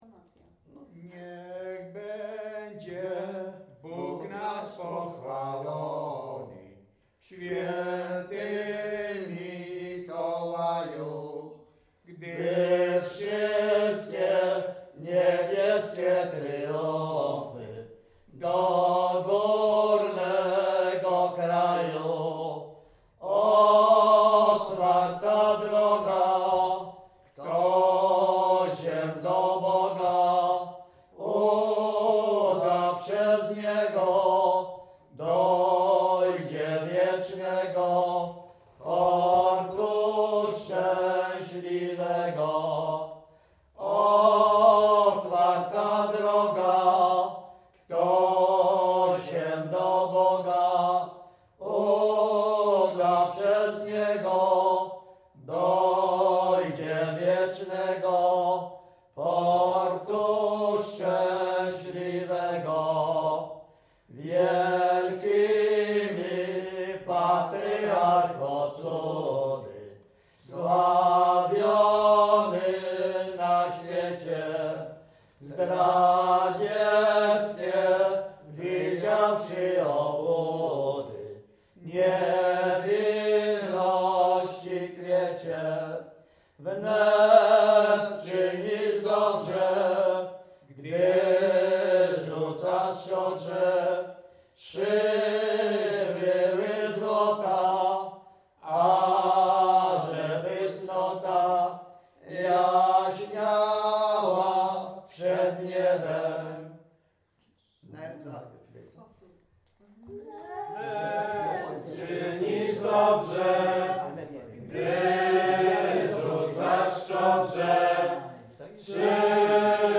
Śpiewacy z Ruszkowa Pierwszego
Wielkopolska, powiat kolski, gmina Kościelec, wieś Ruszków Pierwszy
Pogrzebowa
pogrzebowe nabożne katolickie do grobu o świętych